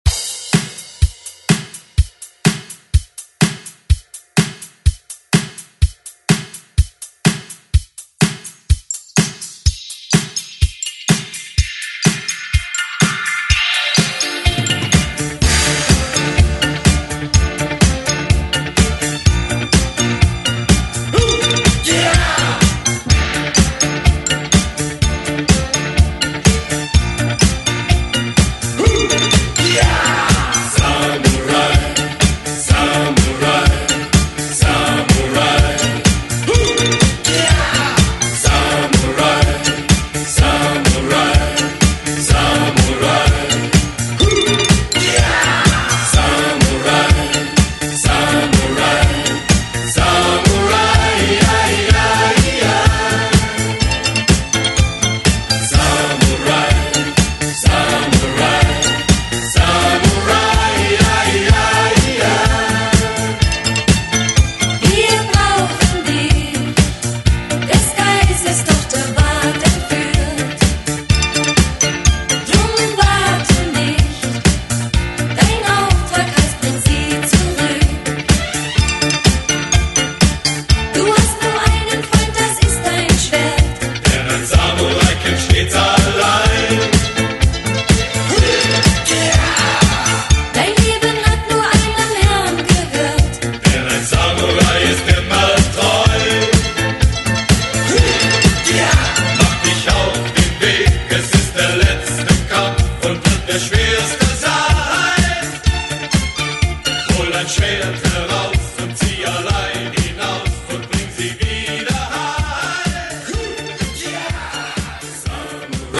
BPM: 125 Time